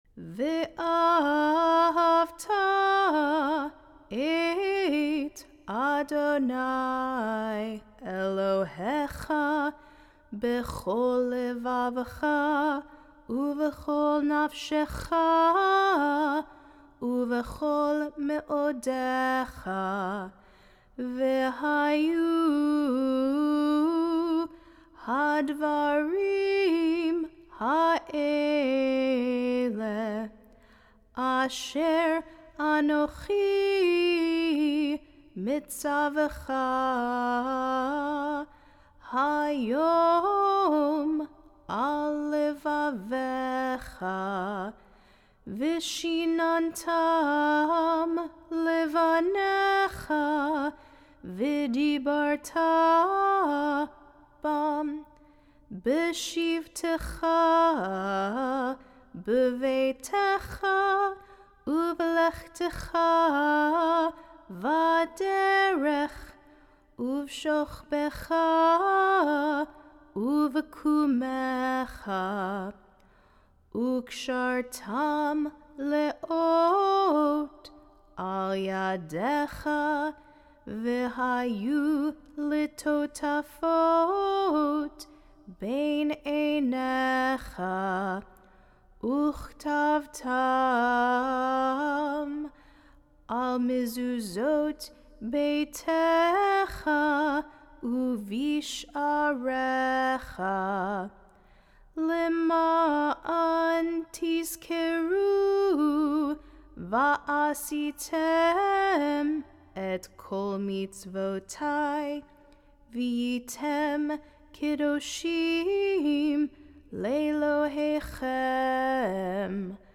sing in Hebrew V’havta.